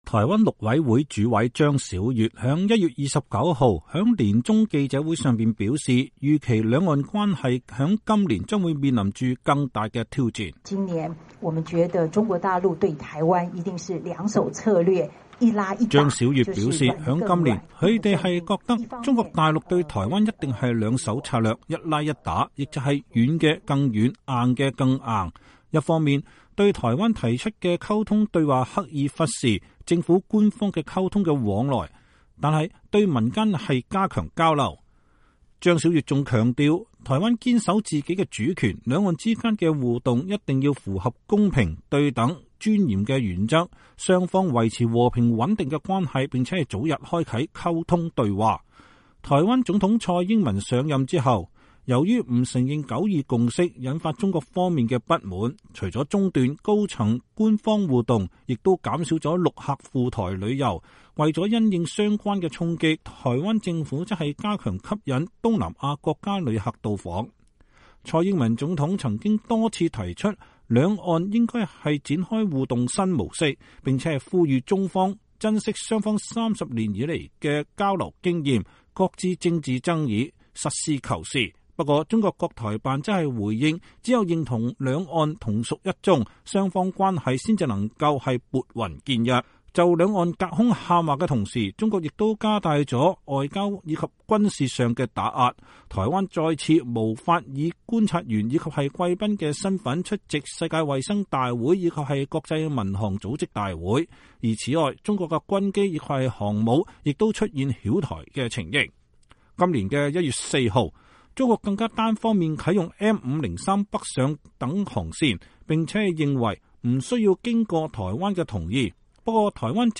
台灣陸委會主委張小月星期一(1月29號)在年終記者會上表示，預期兩岸關係今年將面臨更大的挑戰。